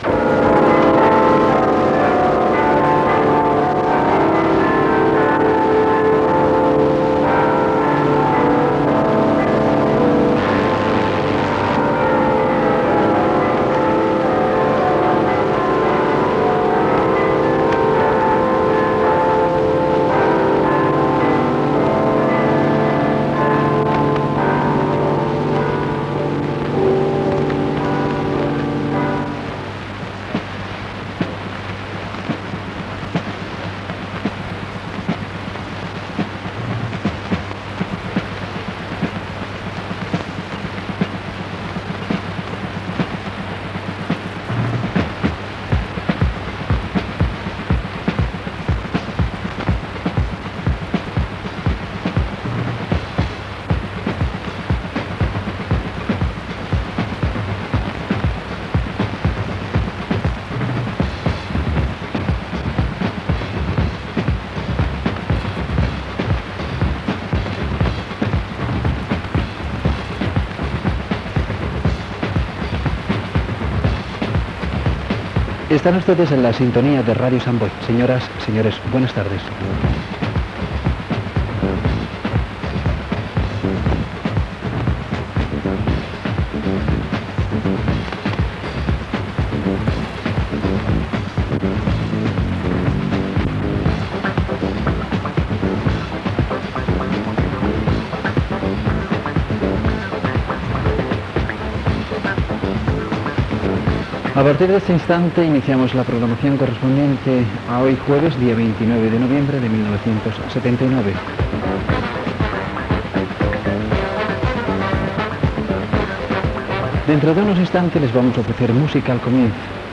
Campanades de sintonia, inici de l'emissió amb la programació del dia.
FM